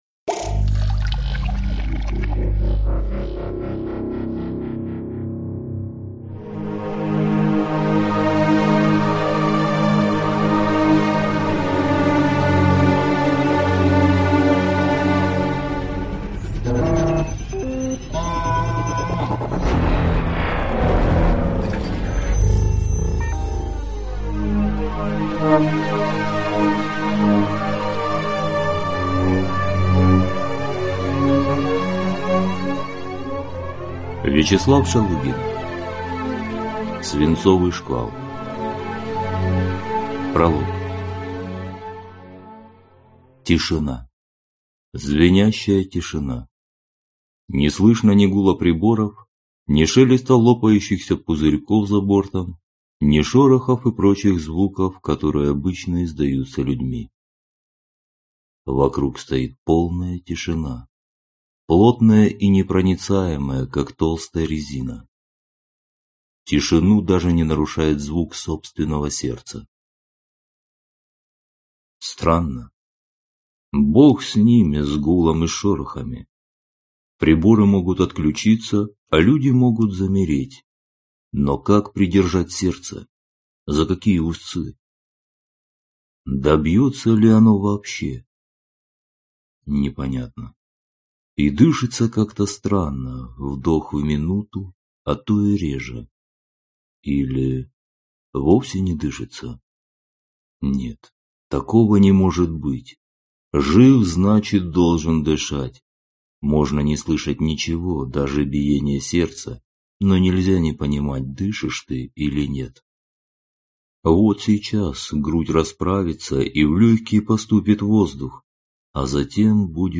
Аудиокнига Свинцовый шквал | Библиотека аудиокниг